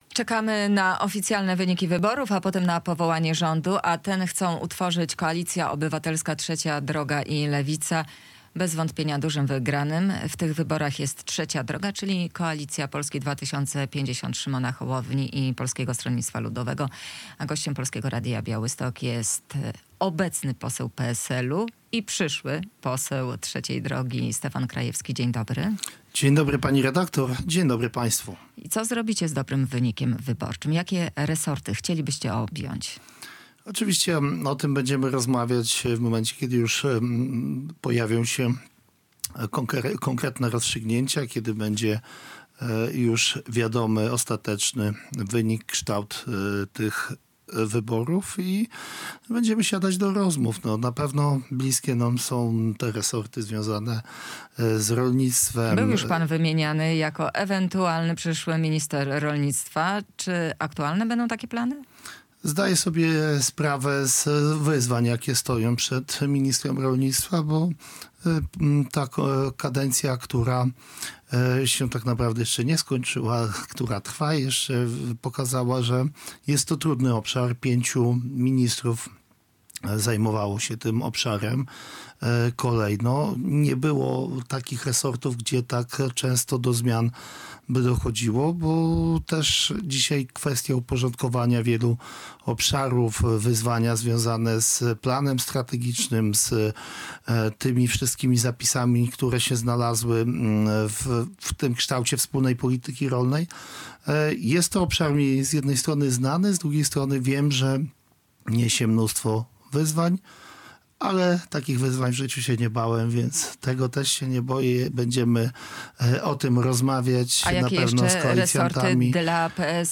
Radio Białystok | Gość | Stefan Krajewski [wideo] - poseł PSL, kandydat Trzeciej Drogi do Sejmu